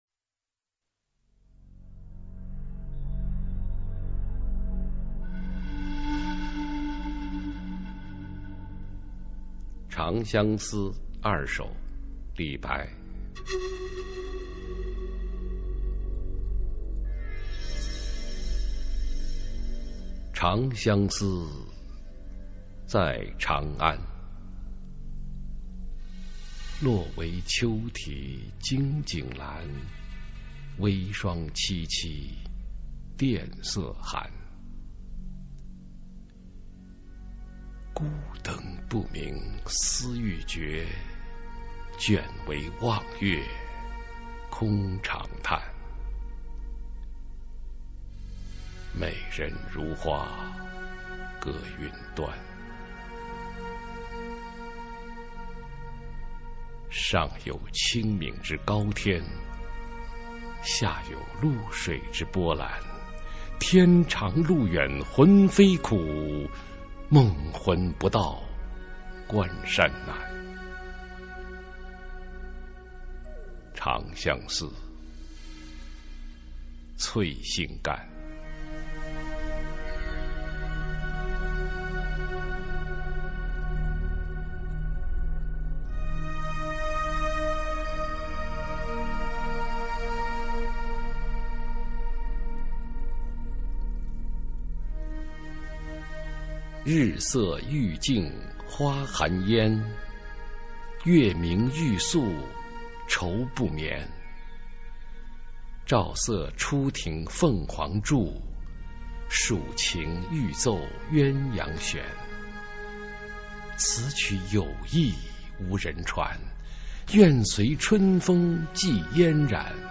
[隋唐诗词诵读]李白-常相思二首 唐诗吟诵